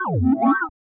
compost_level_up.wav